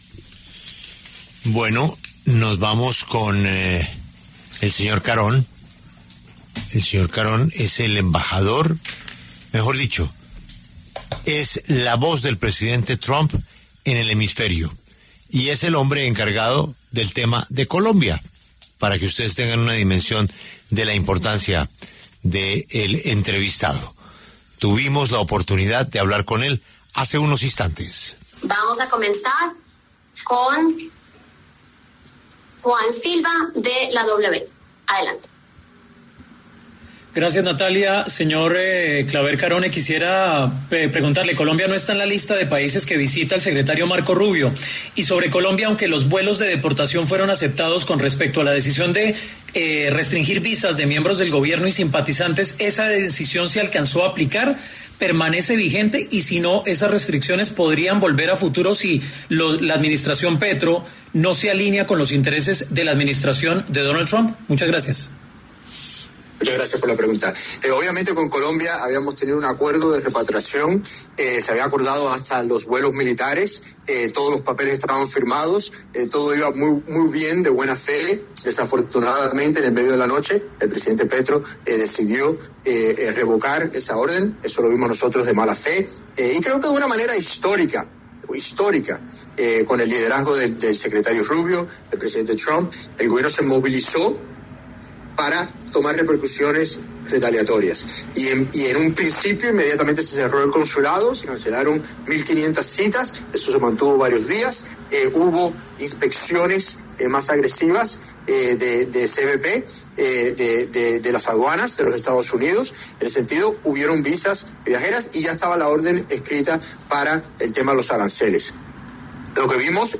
En declaraciones ante medios de comunicación en Washington, Mauricio Claver Carone explicó detalles del impasse y las restricciones de visa.
Este viernes, desde Washington, Mauricio Claver-Carone, enviado especial para asuntos de América Latina, respondió a una pregunta de W Radio sobre el reciente episodio diplomático entre Colombia y Estados Unidos.